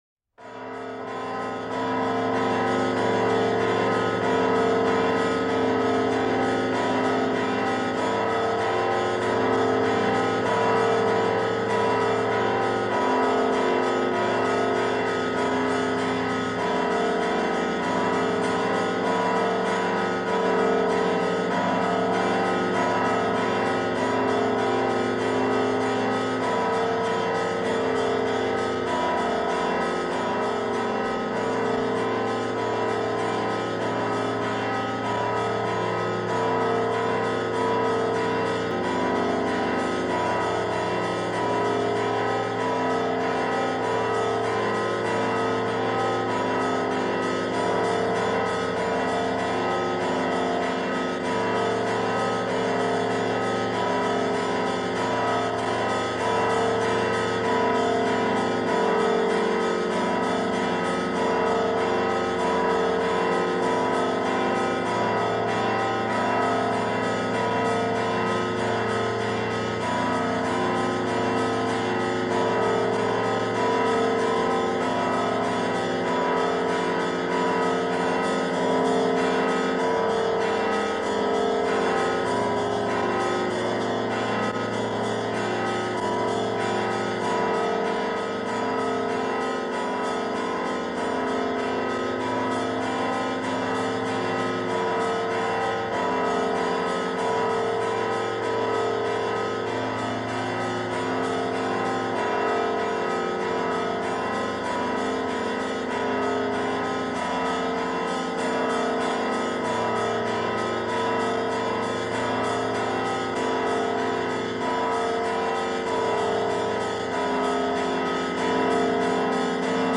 Категория: Духовная музыка
Колокольный звон на коронацию
06_Kolokolnyj_Zvon_Na_Koronaciju.mp3